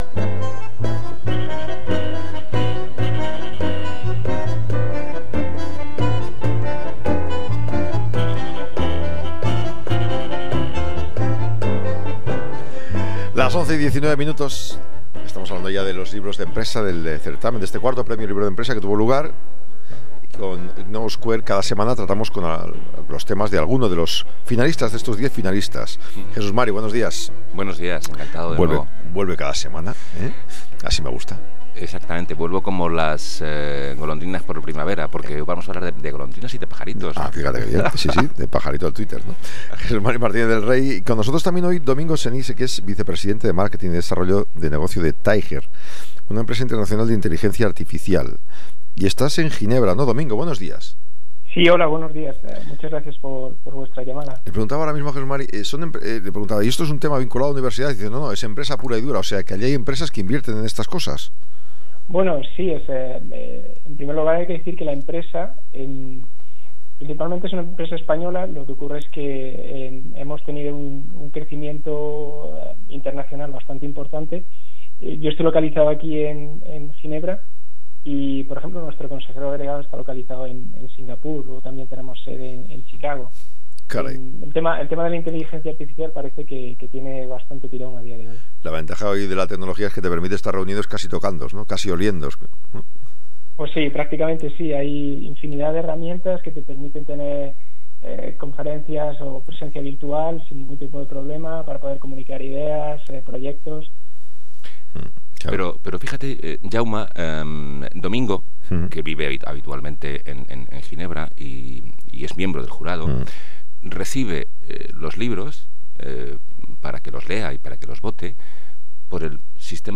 Entrevista en Gestiona Radio: La verdadera historia de Twitter de Nick Bilton